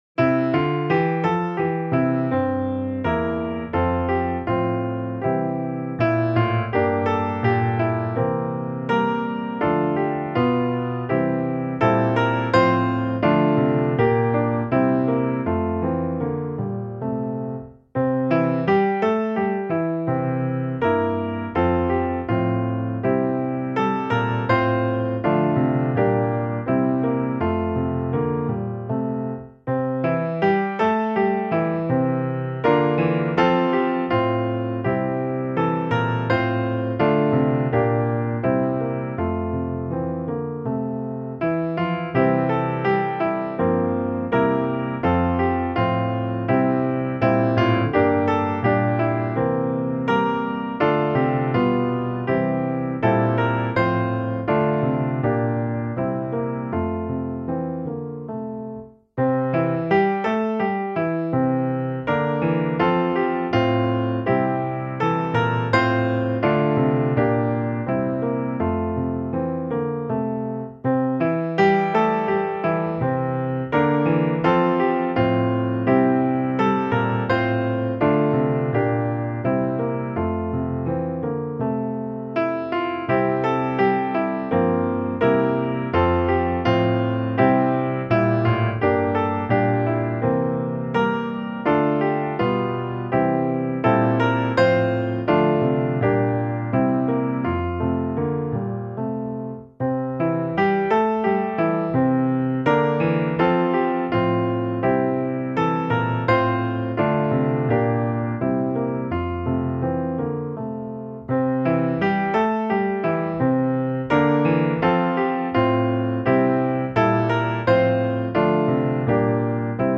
I himmelen, i himmelen, B - musikbakgrund
Gemensam sång
Musikbakgrund Psalm